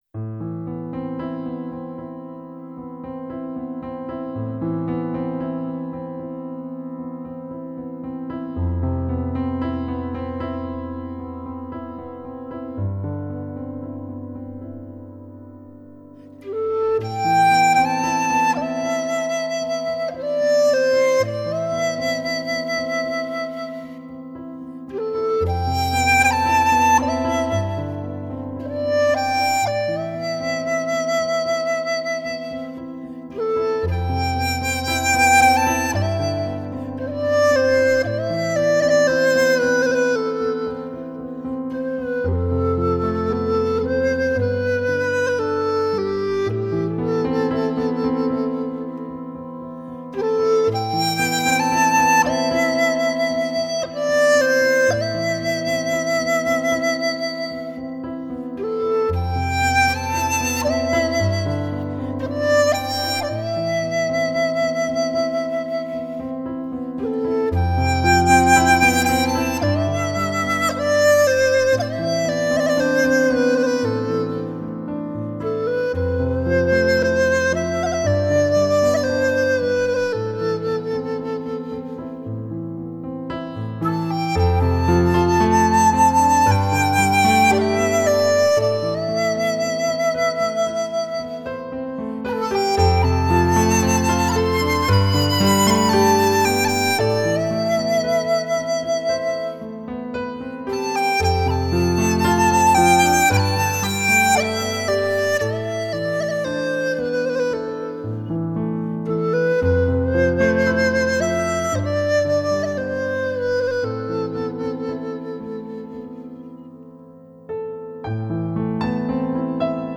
笛